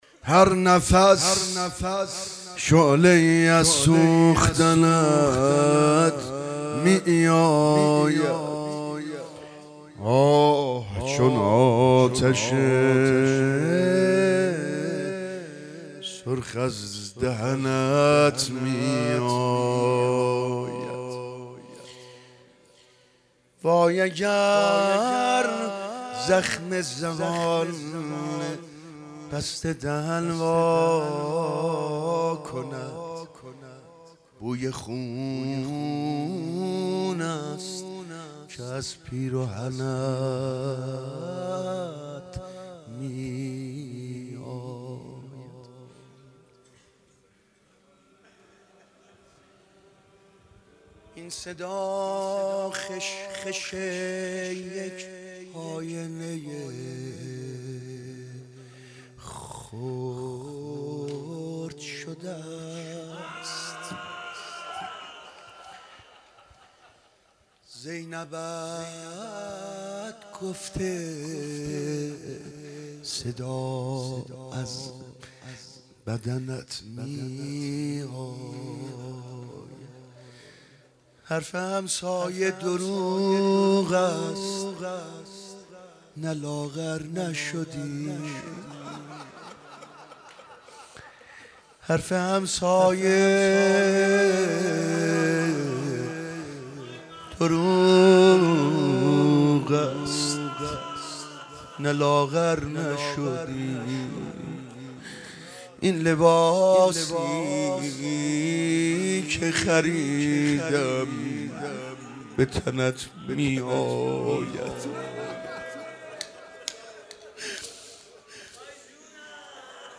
هرنفس شعله ای از سوختنت می آید - شعرخوانی و روضه
هیئت هفتگی